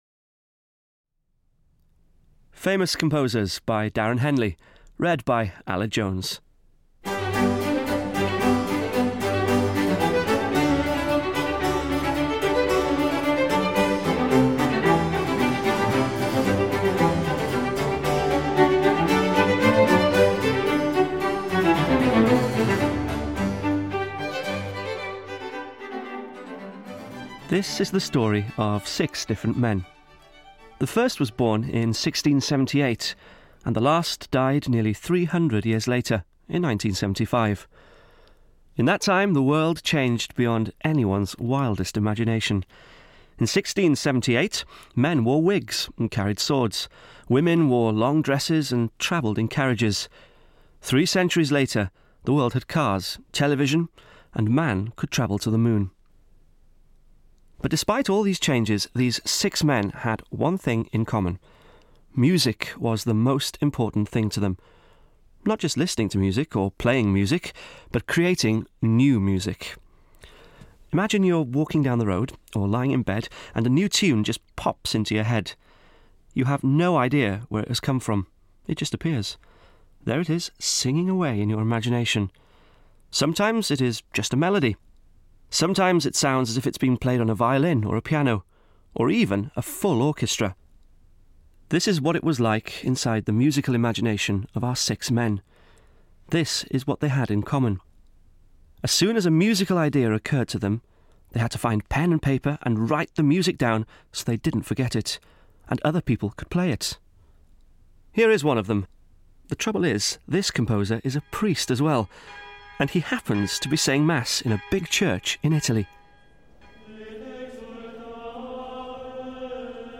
Audio knihaFamous Composers (EN)
Ukázka z knihy
• InterpretAled Jones